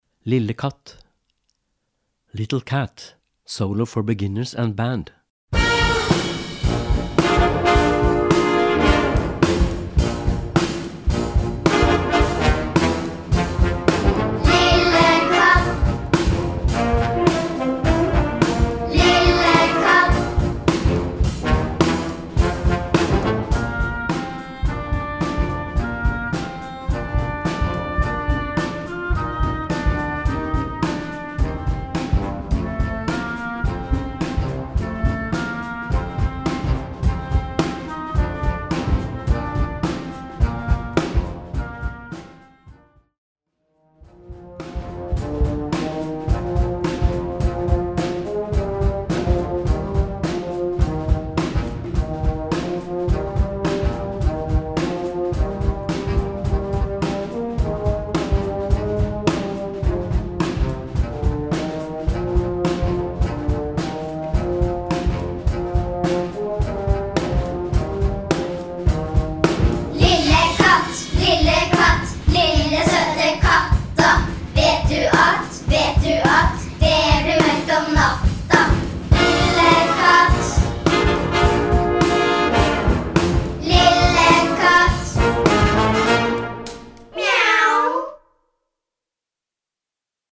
Gattung: Solo for Beginners and Band CB1
Besetzung: Blasorchester